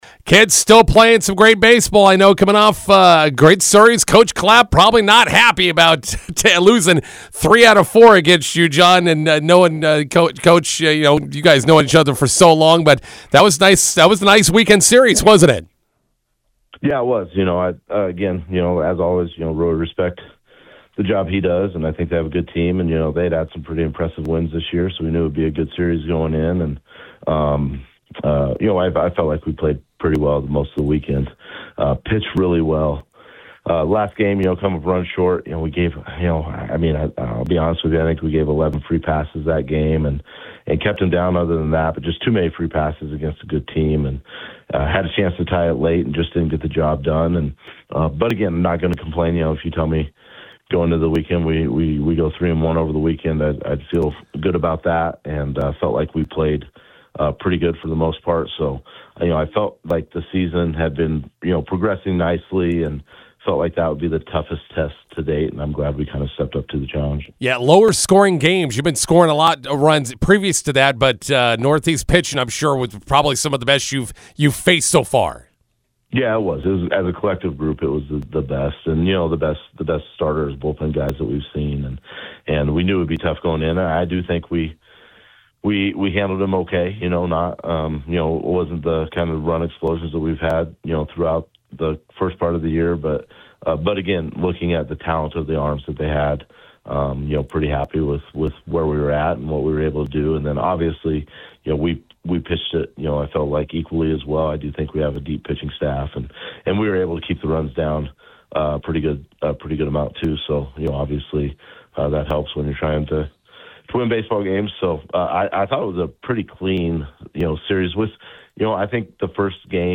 INTERVIEW: McCook Community College baseball wins three of four games vs. Northeast of Norfolk, head to WNCC today.